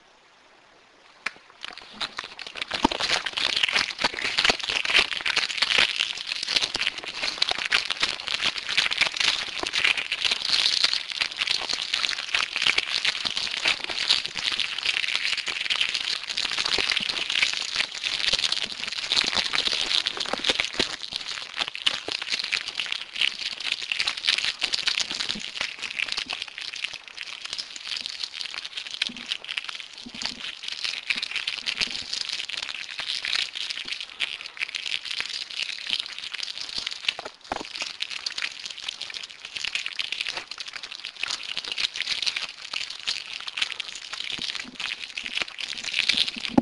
描述：记录我酒店房间的背景噪音。这应该是一家好的酒店，但在我的房间里，我可以听到电梯的声音，其他房间的电视噪音，人们的谈话和走动。可怕的地方。索尼PCMD50
Tag: 无人驾驶飞机 现场记录 阻碍 度假 酒店 旅店 低噪音 噪音大 扰民 房间 隆隆